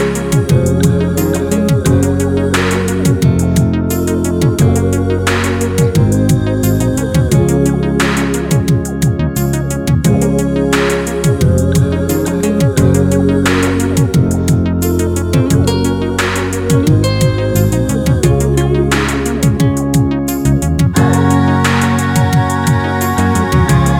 End Cut Down Reggae 3:57 Buy £1.50